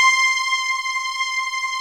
CRYSTAL C5.wav